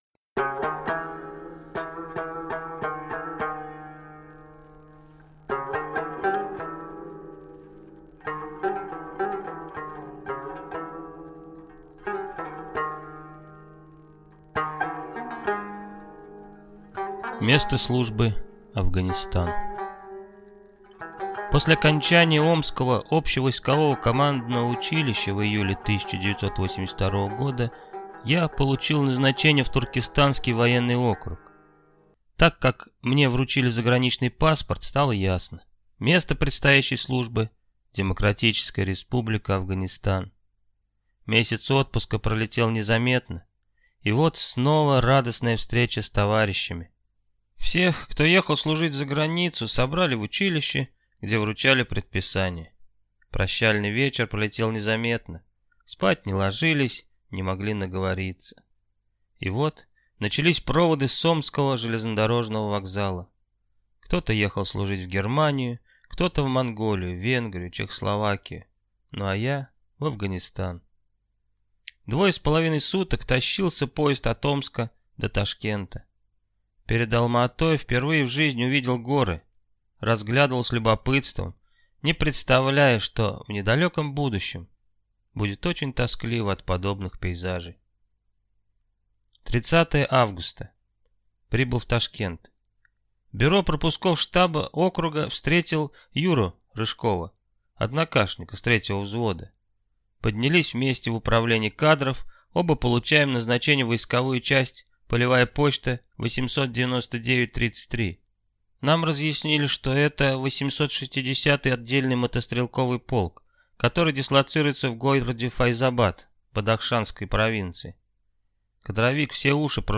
Аудиокнига Афганский дневник пехотного лейтенанта. «Окопная правда» войны | Библиотека аудиокниг